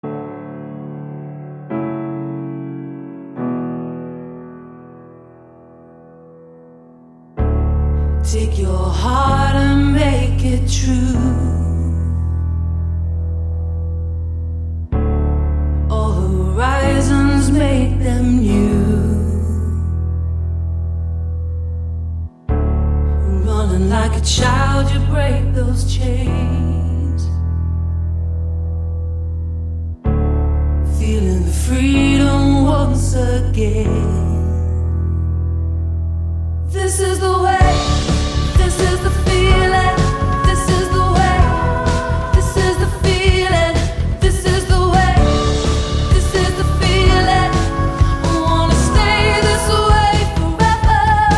• Качество: 128, Stereo
поп
женский вокал
спокойные
нарастающие
пианино
медленные
Спокойный приятный рингтон.